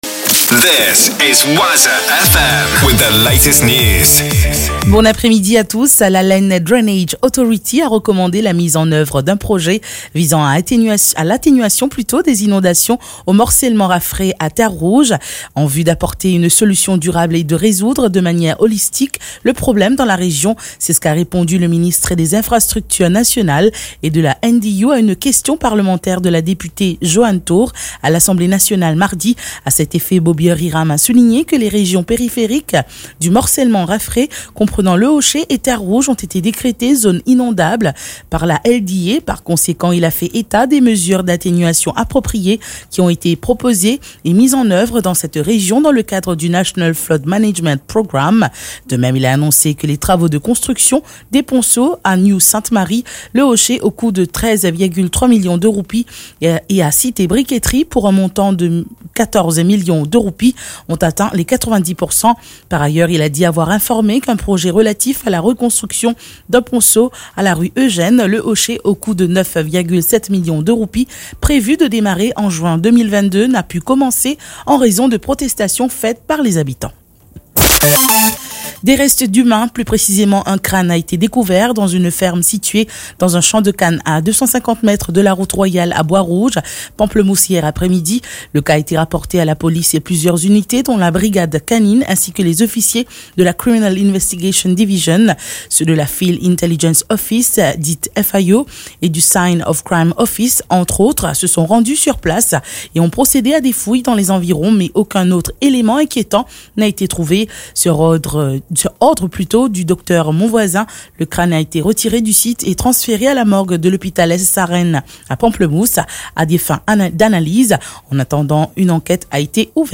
NEWS 15H - 9.11.23